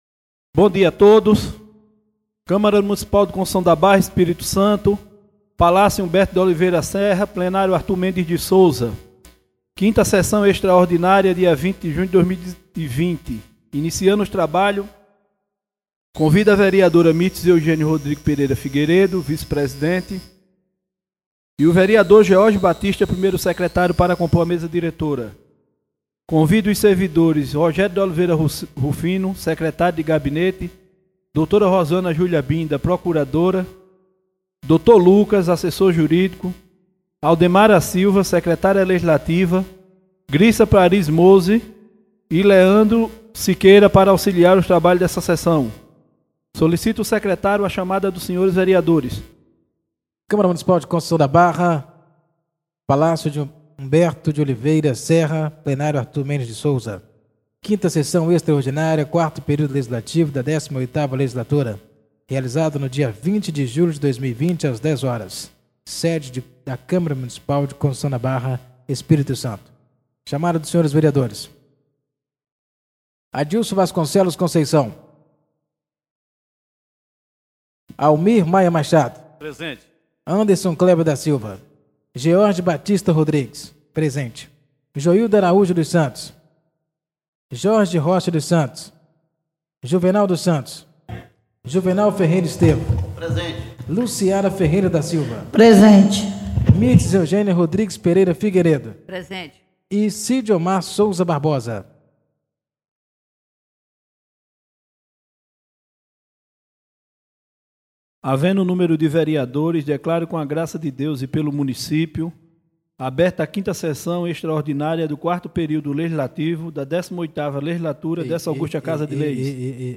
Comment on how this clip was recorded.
5ª Sessão Extraordinária do dia 20 de Julho de 2020